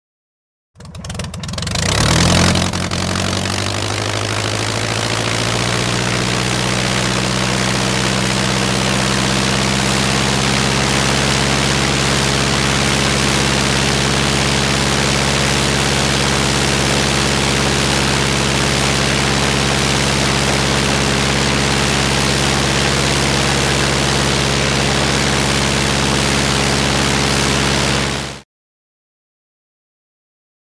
Prop Plane; Idle; Fokker Single Engine Prop Aircraft Circa 1914 High Steady Revs. Good For Constant In Flight.